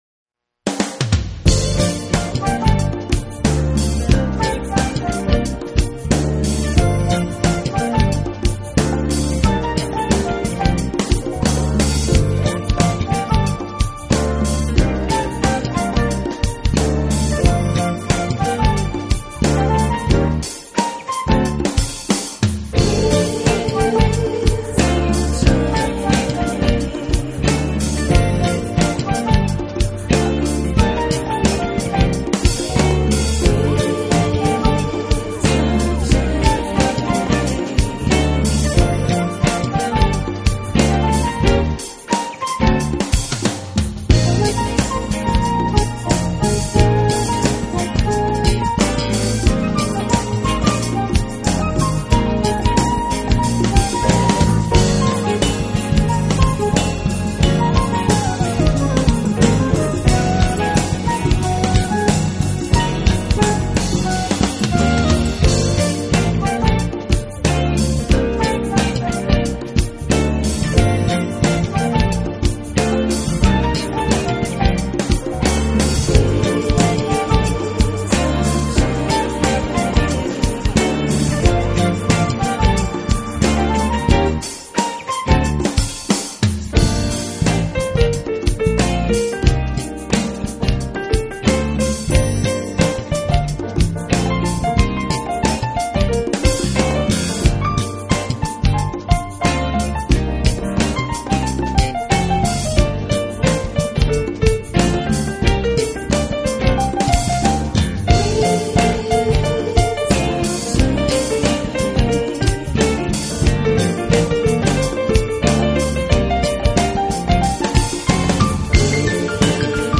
音乐风格: Smooth Jazz
炫动的节奏让你感觉驰骋在浩渺的大海之上，岛屿的和城市的热情被烙上了漂亮的jazz印记。
坐下来欣赏一下这欢快的节奏，让身心得以放松，享受这美妙的一刻。